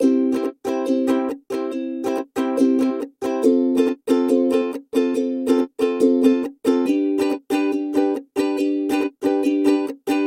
Звуки укулеле
Простая мелодия на укулеле в цикле